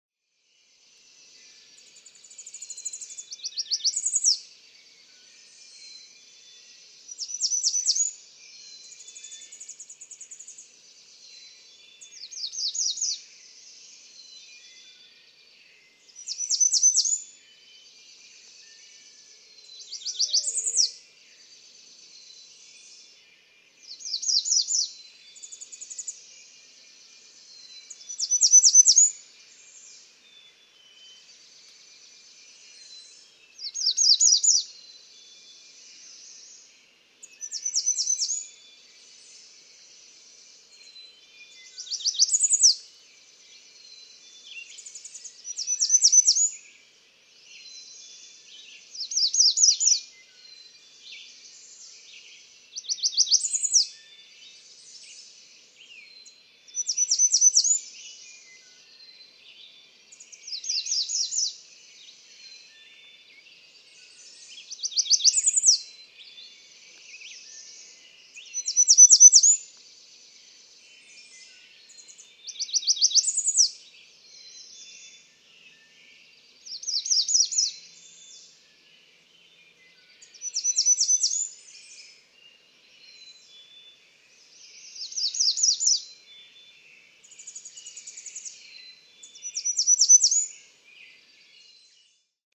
American redstart
A third male with unique, identifiable dawn songs in the 2014 neighborhood.
Cricket Hill, Conway, Massachusetts.
563_American_Redstart.mp3